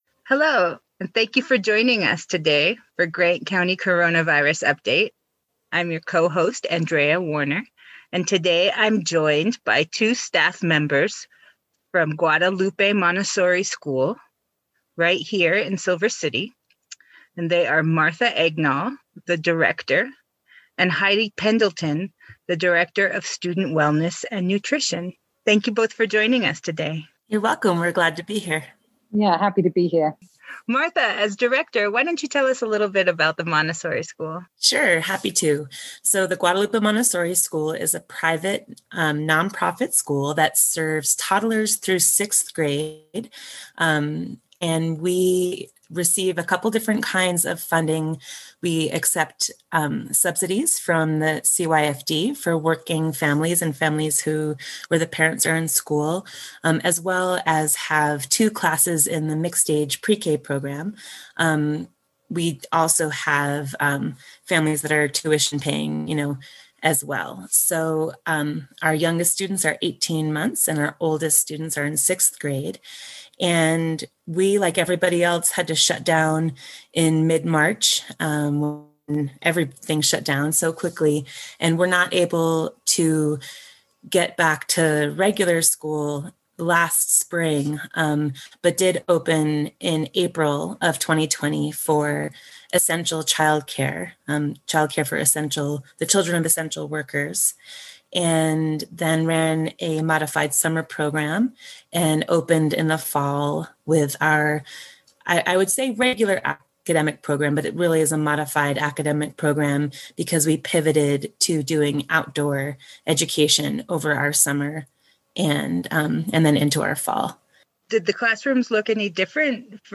Coronavirus Update is broadcast live on Gila/Mimbres Community Radio KURU 89.1 FM in Silver City.